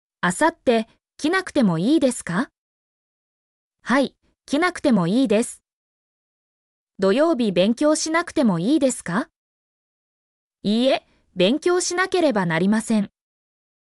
mp3-output-ttsfreedotcom-15_ykhfnHTk.mp3